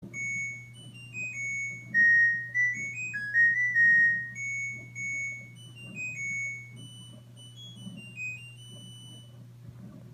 Звуки стиральной машины
Звук стиральной машины LG после окончания стирки с писком